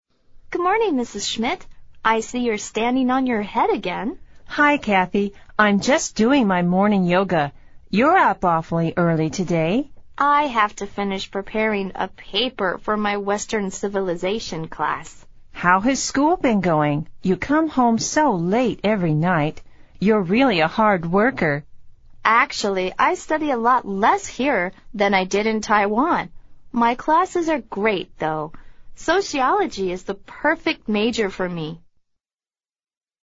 美语会话实录第109期(MP3+文本):Morning yoga